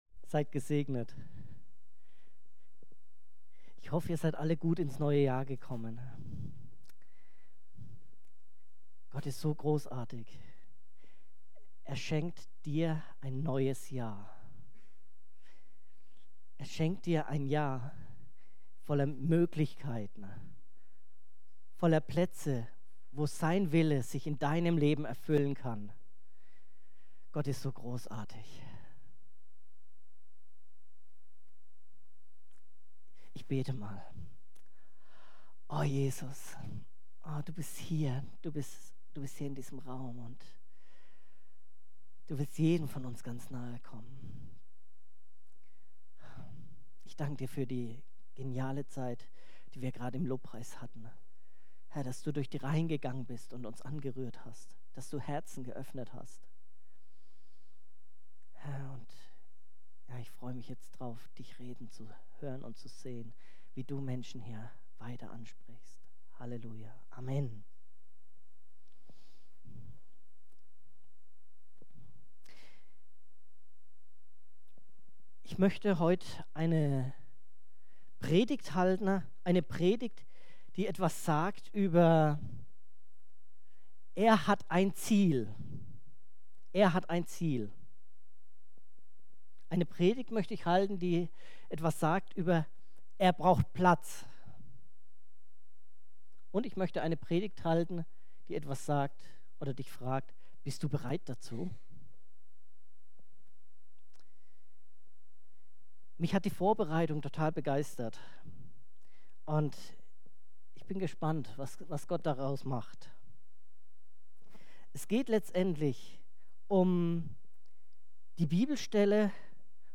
Predigten